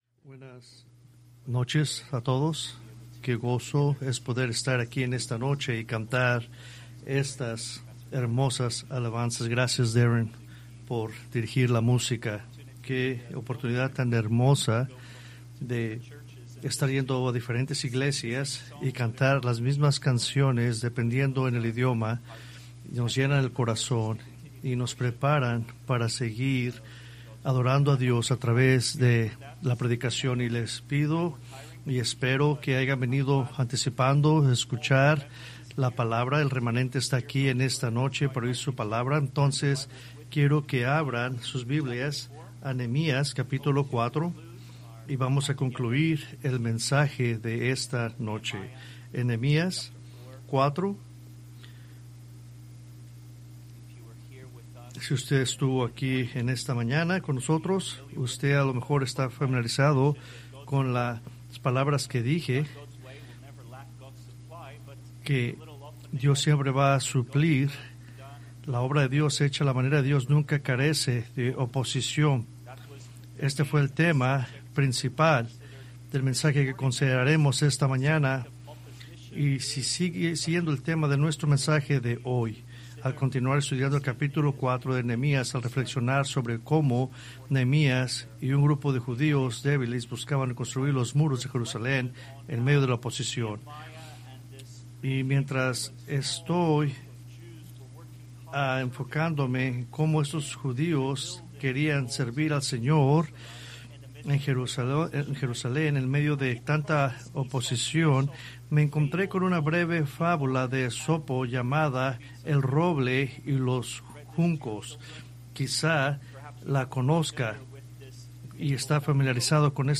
Preached March 1, 2026 from Nehemías 4:7-14